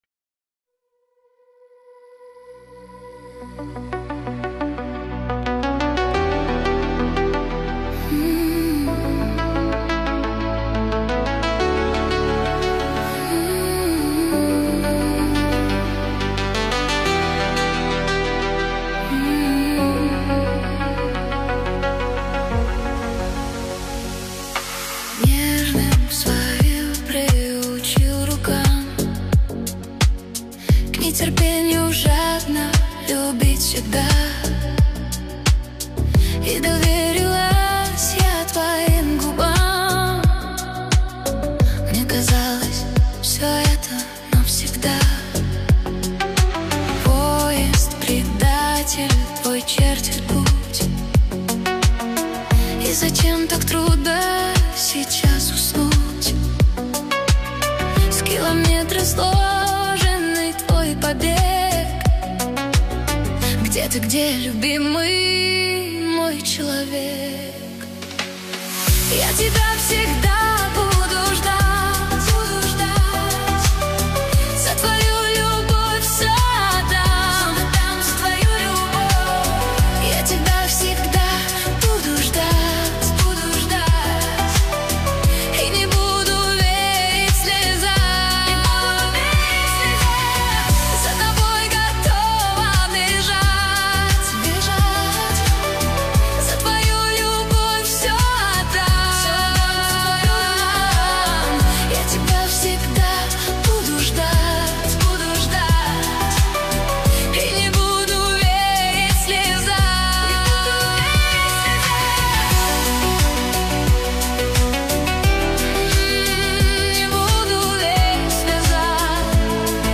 Ремиксы
кавер ремикс